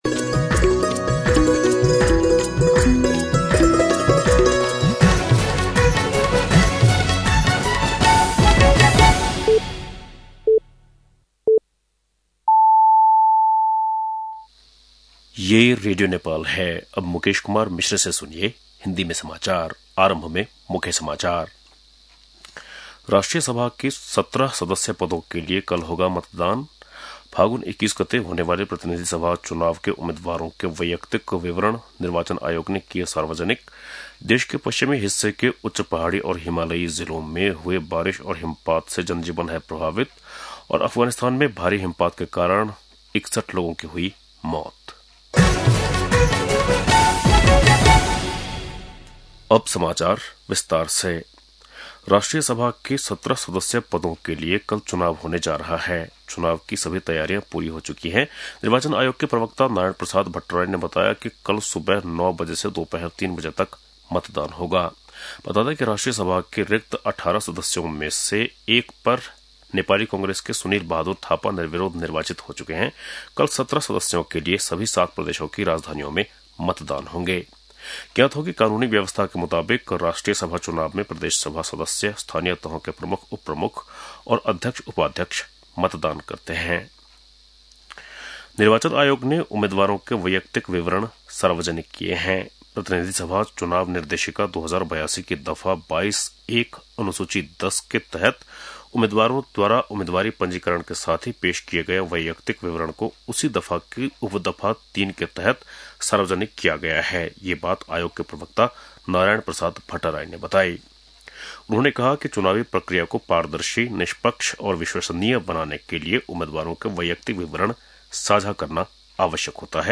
बेलुकी १० बजेको हिन्दी समाचार : १० माघ , २०८२
10-pm-News.mp3